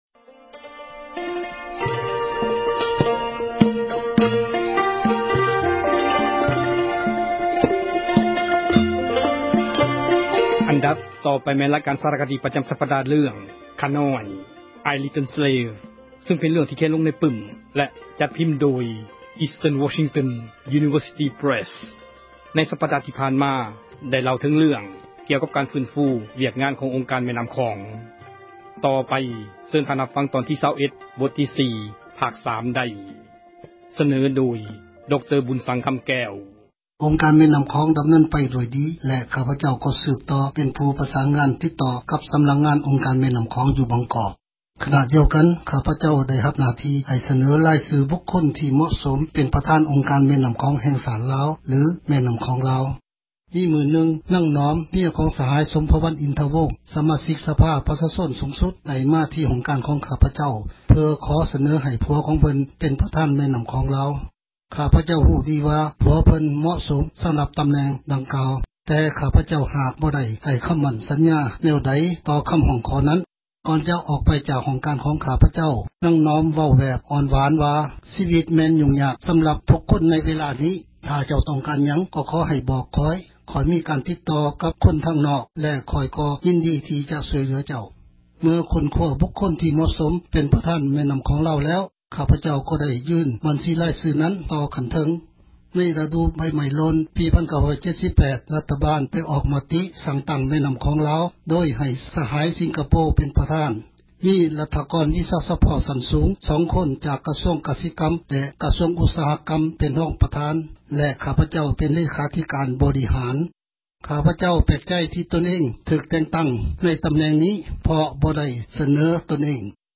ສາຣະຄະດີ ເຣື່ອງ ”ຂ້ານ້ອຍ" (I Little Slave) ພາຄ 21 ສເນີໂດຍ